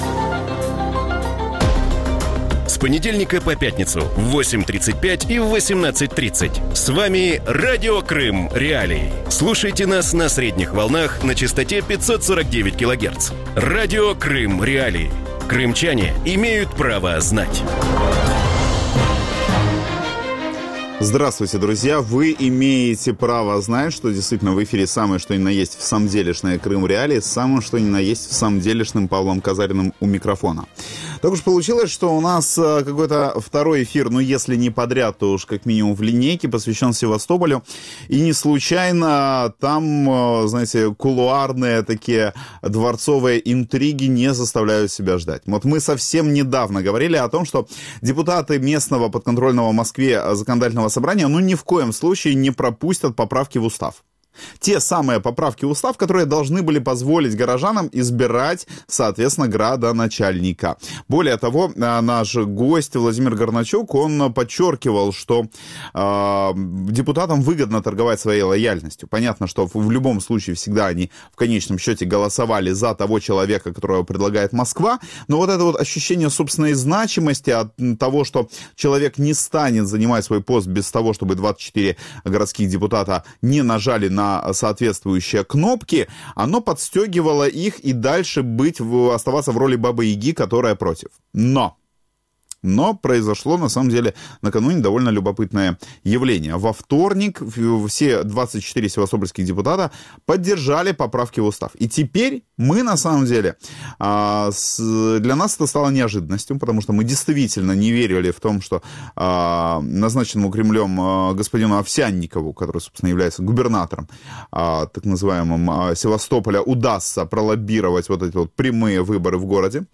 В вечернем эфире Радио Крым.Реалии обсуждают принятие в первом чтении законопроекта о прямых выборах подконтрольного Кремлю губернатора Севастополя. Почему российские депутаты города изменили свое решение, откуда исходит инициатива о прямых выборах градоначальника и что о прямых выборах думают в Кремле?